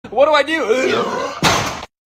Burp) Omg What Do I Do